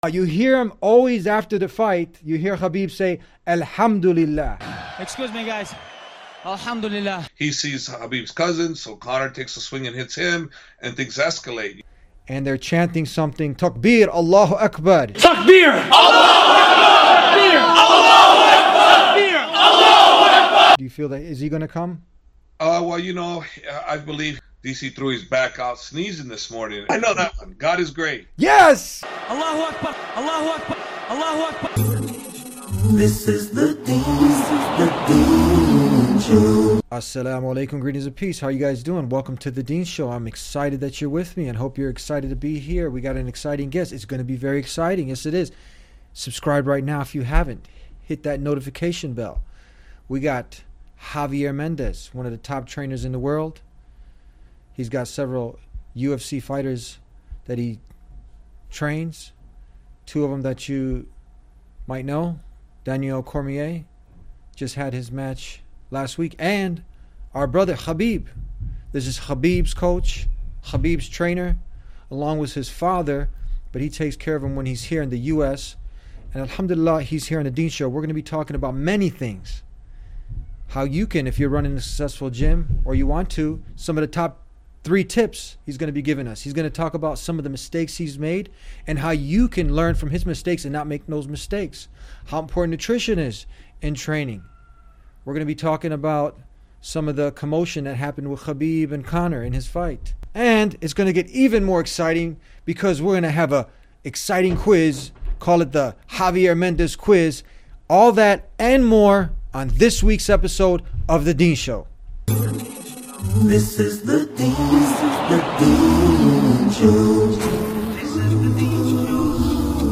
Javier Mendez (Khabib’s coach) takes Quiz on Khabib Nurmagomedov